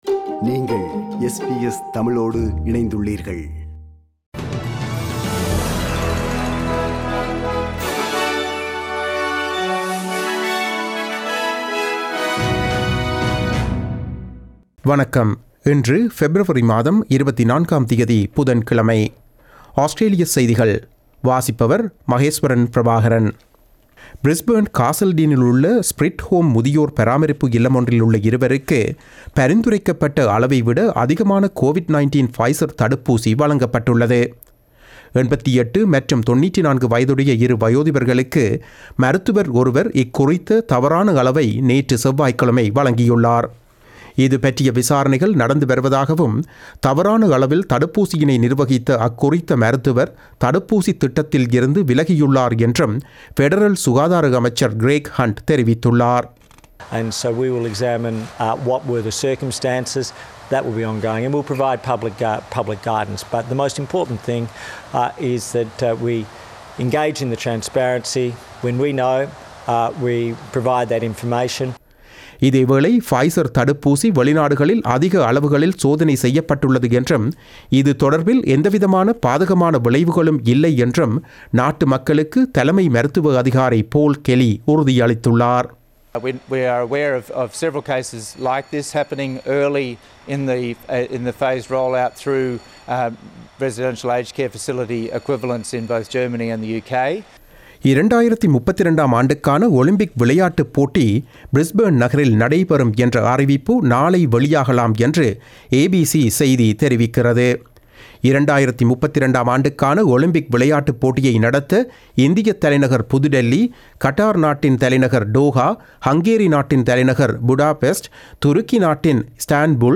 Australian news bulletin for Wednesday 24 February 2021.